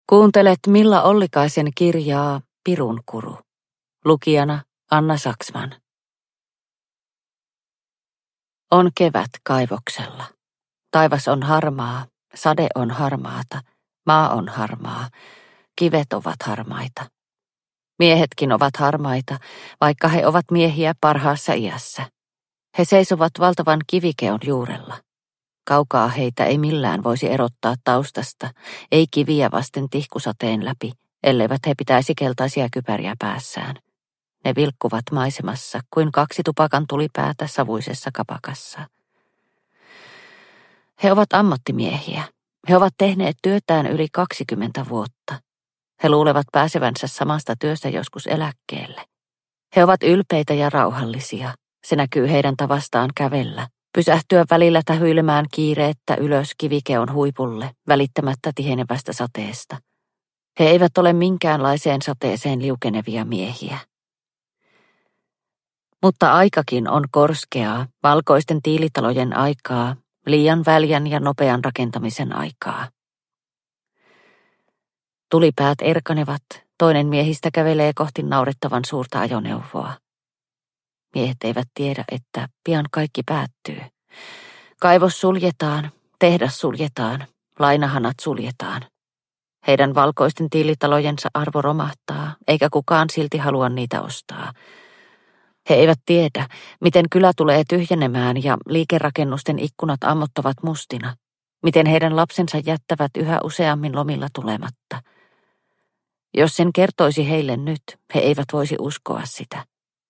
Pirunkuru – Ljudbok – Laddas ner